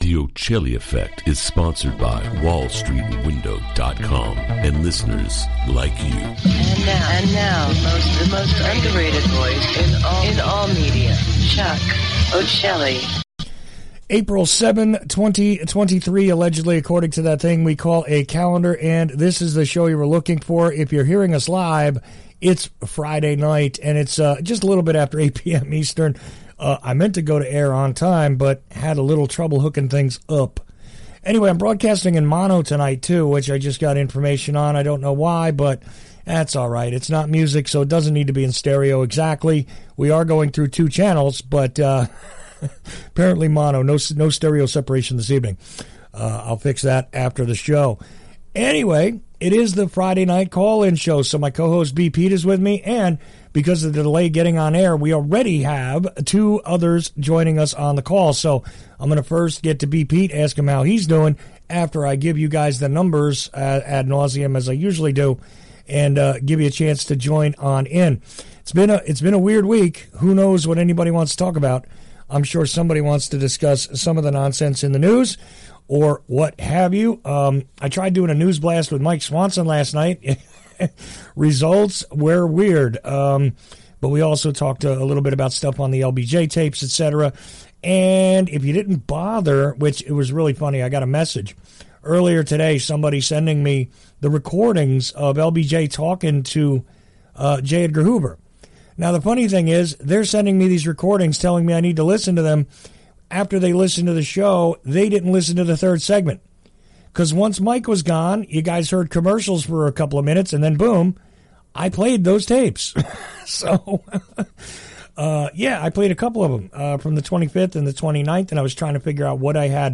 Unscripted call-ins happened on the Friday Night Open Mic broadcast. What transition makes the sports world go around?Is the News worth discussing?